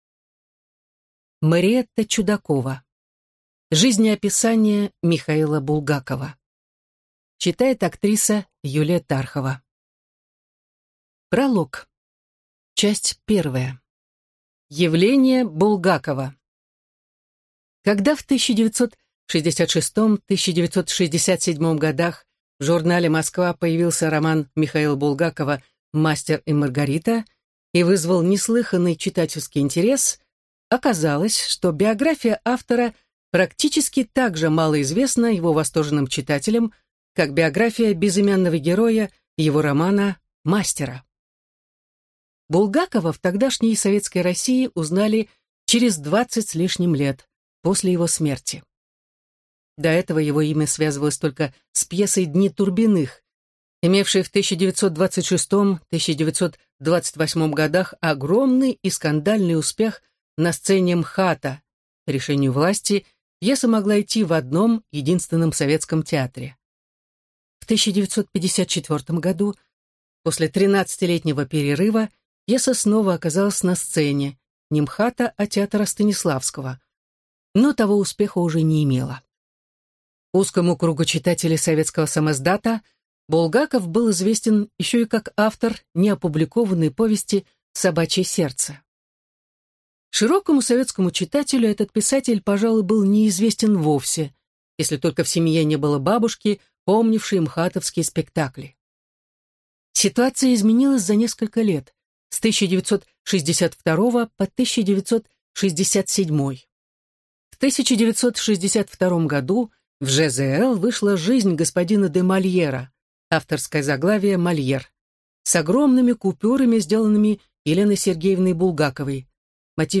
Аудиокнига Жизнеописание Михаила Булгакова | Библиотека аудиокниг